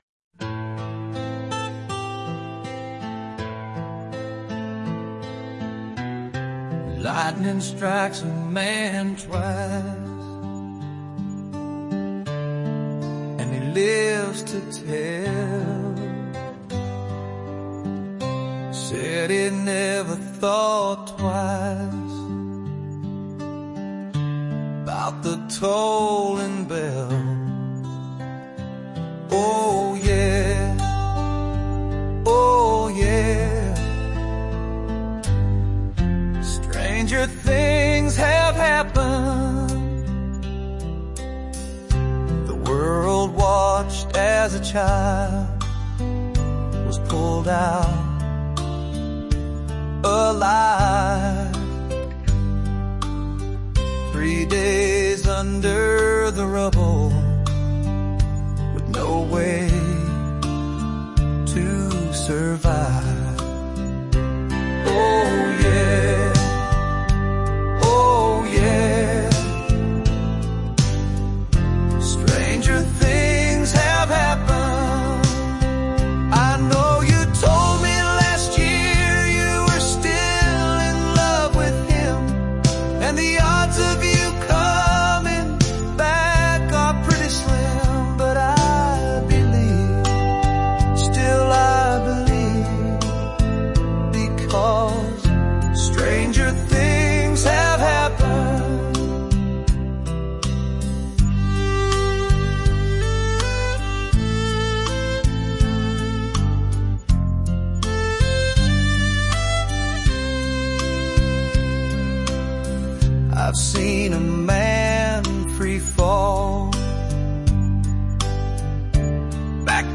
revised with music and vocals July01,2025